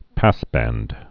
(păsbănd)